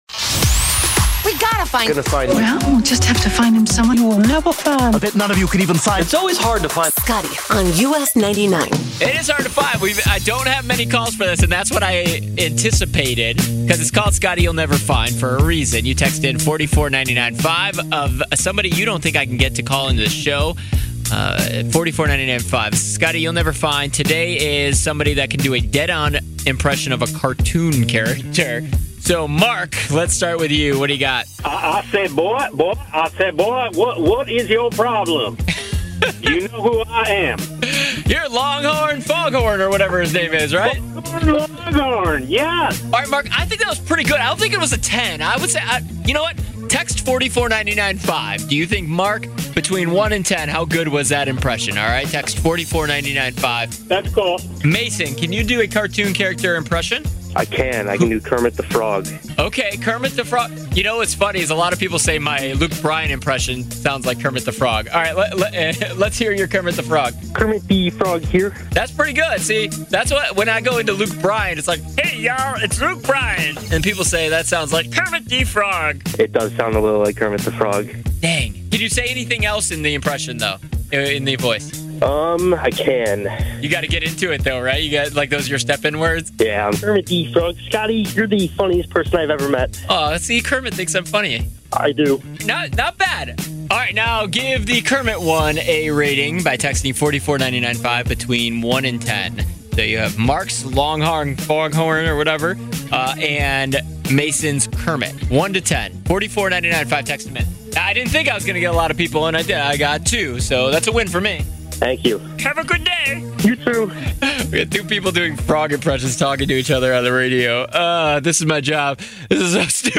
Cartoon Impersonation Voices